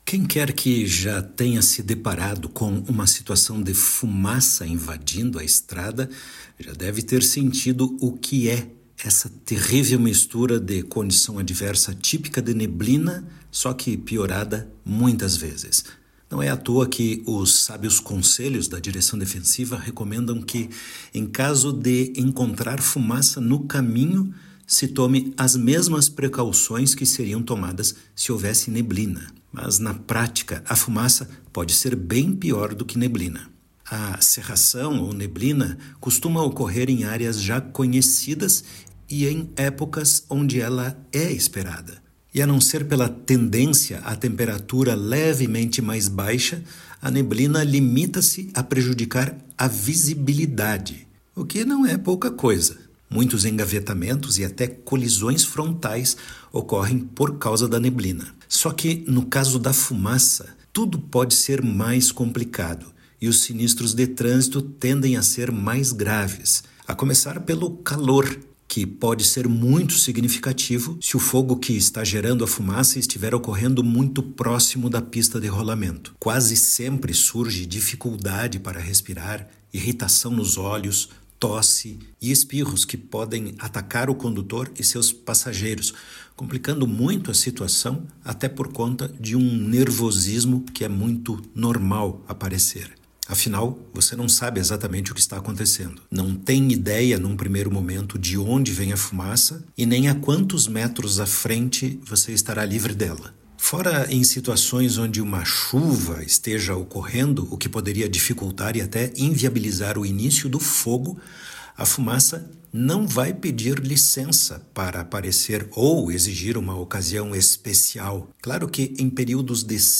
Ouça o áudio completo do especialista: